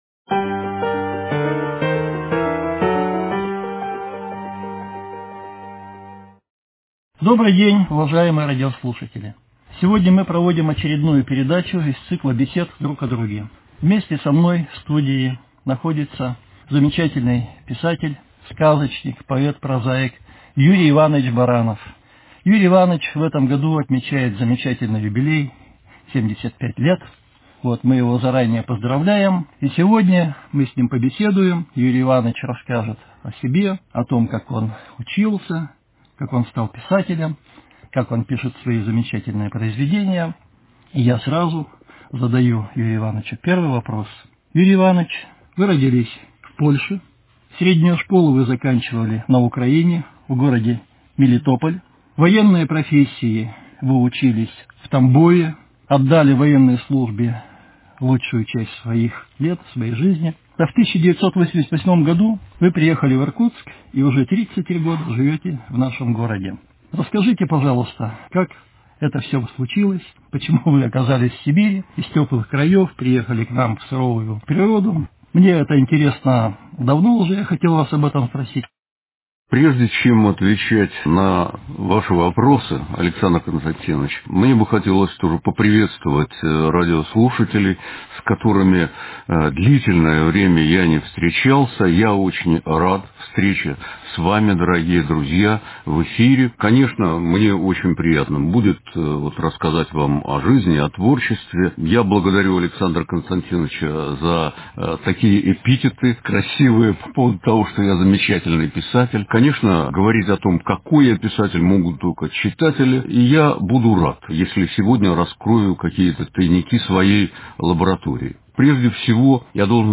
Радио-беседа